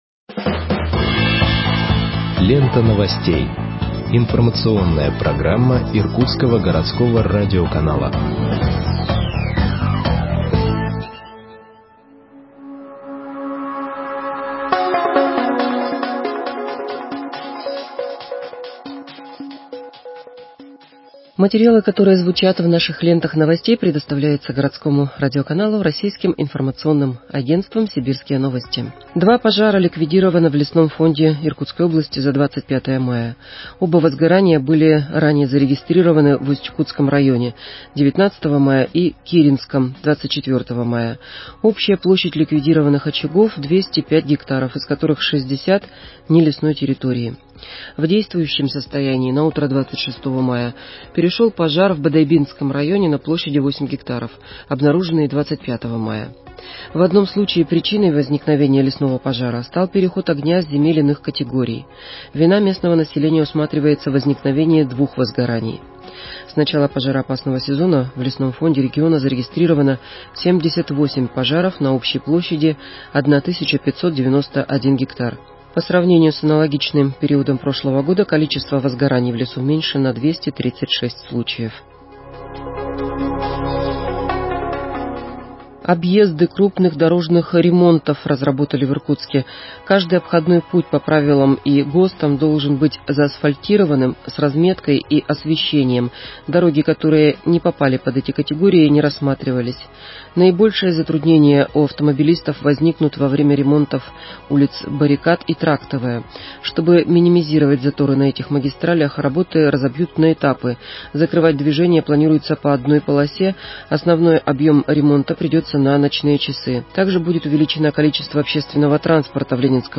Выпуск новостей в подкастах газеты Иркутск от 26.05.2021 № 2